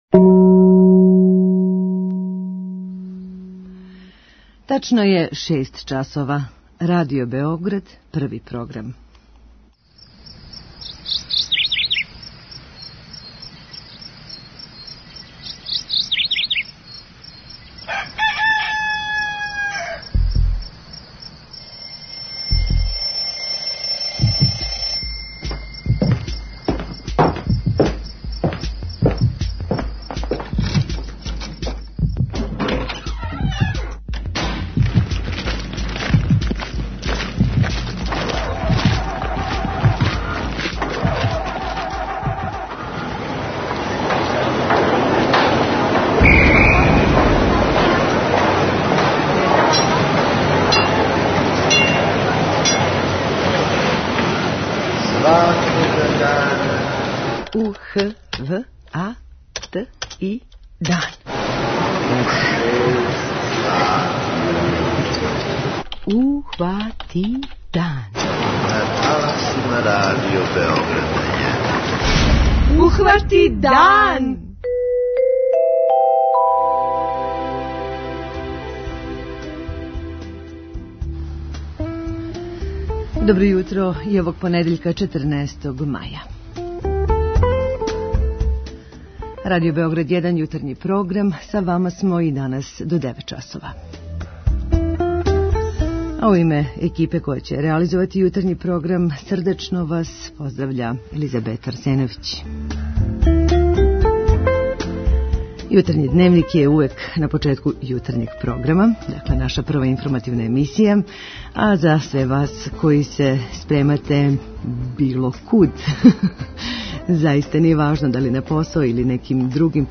преузми : 32.35 MB Ухвати дан Autor: Група аутора Јутарњи програм Радио Београда 1!